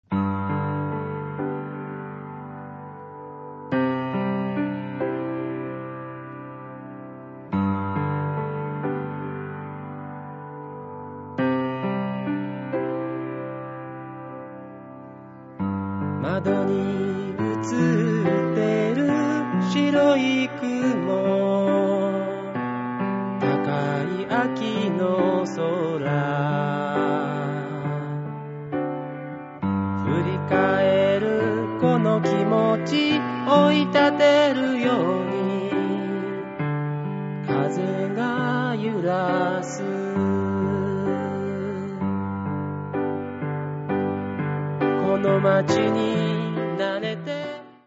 • ファイルサイズ軽減のため、音質は劣化しています。
歌・ピアノ演奏